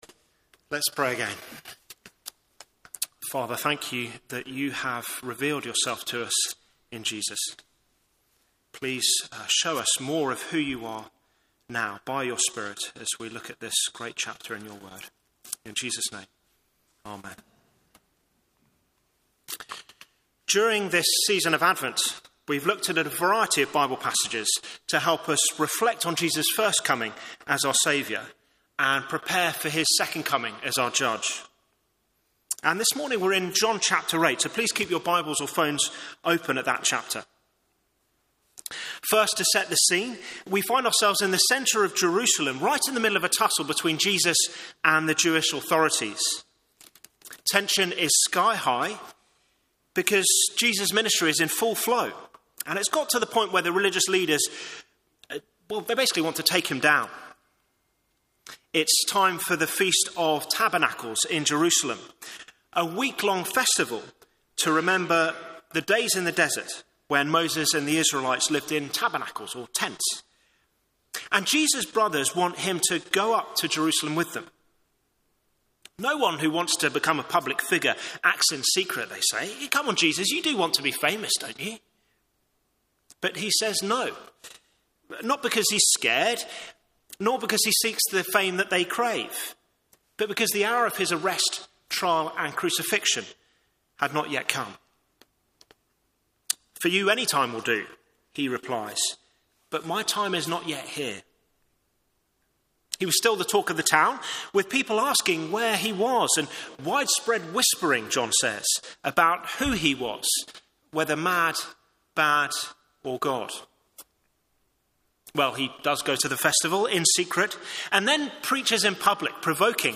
Media for Morning Service on Sun 22nd Dec 2024 10:30 Speaker: Passage: Isaiah 9:1-7, John 8:12-20 Series: Theme: Sermon In the search box please enter the sermon you are looking for.